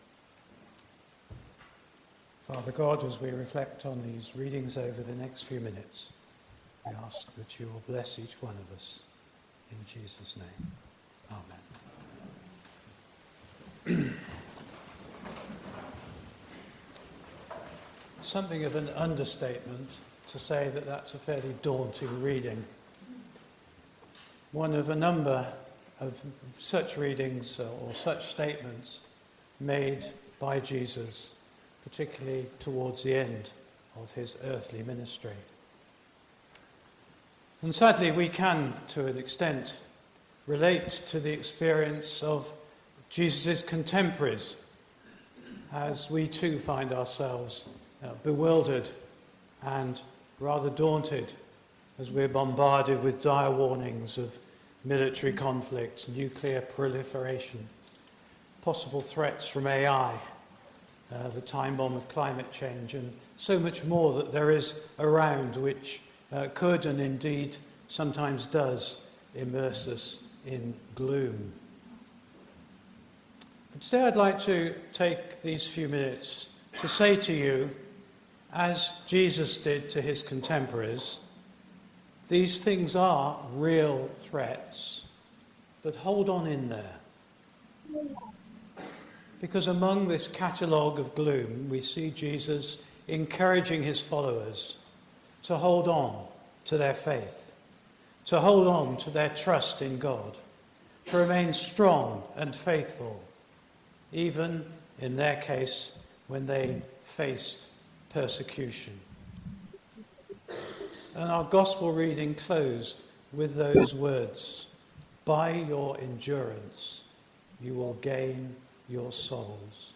This is the word of the Lord All Thanks be to God Series: Ordinary Time , Sunday Morning